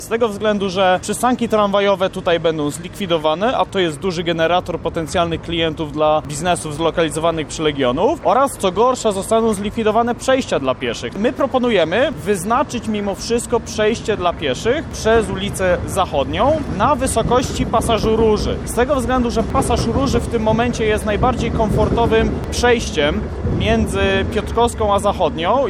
SERWIS-LEGIONOW-ZACHODNIA.mp3